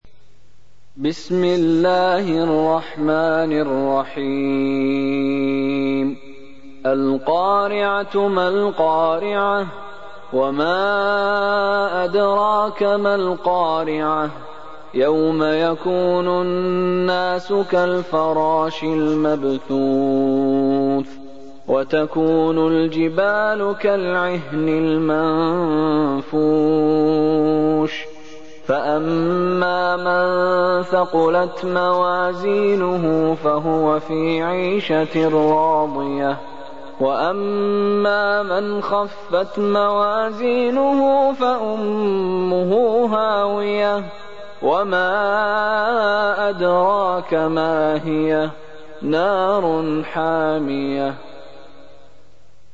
Islam-media, récitateurs du coran : meshary du koweit
Coran : Shaikh Mishary Al-Efasy